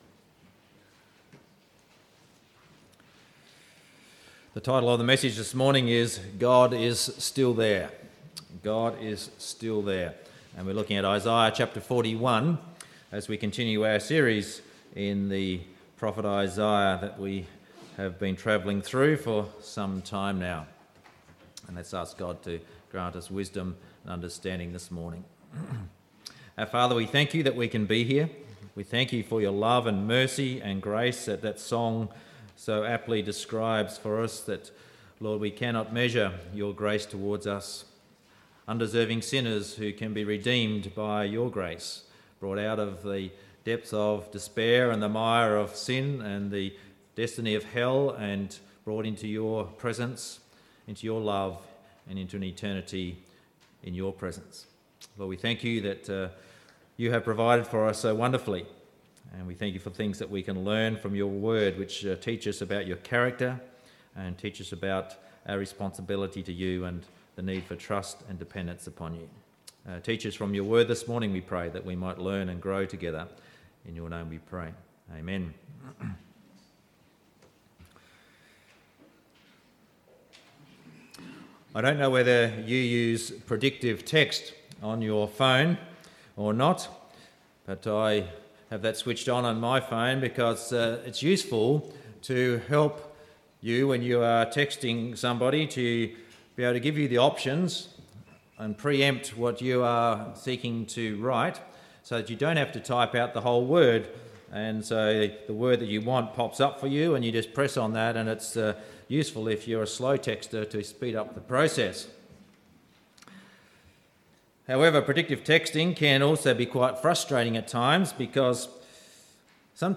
Sunday Service Audio 22/10/17